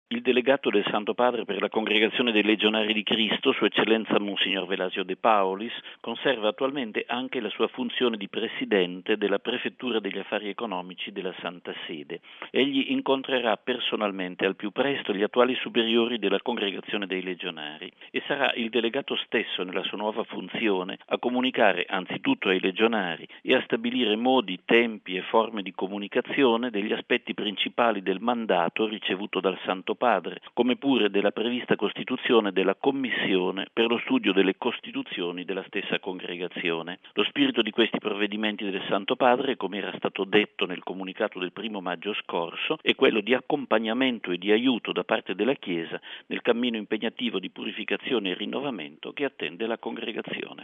Ascoltiamo in proposito il direttore della Sala Stampa vaticana, padre Federico Lombardi: